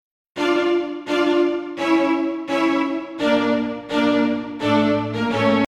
A Higher Pitched Dramatic Ringtone.